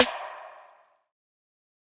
Mood Snare.wav